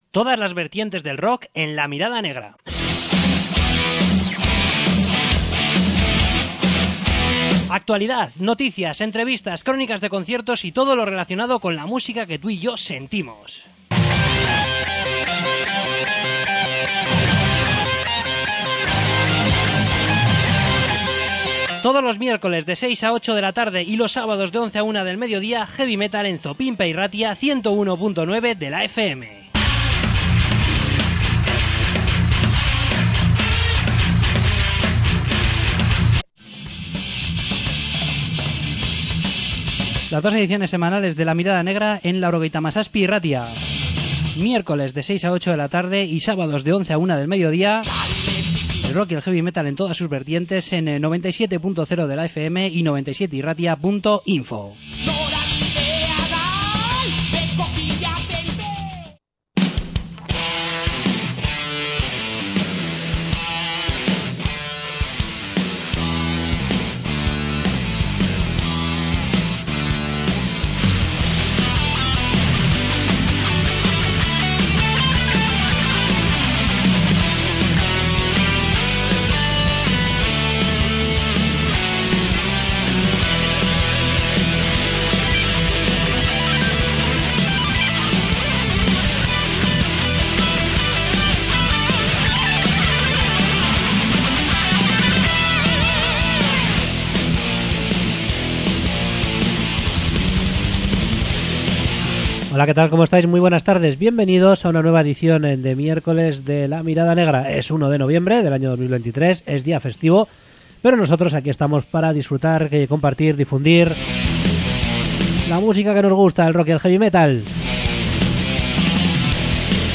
Entrevista con Putrid Torso